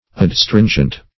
Adstringent \Ad*strin"gent\, a.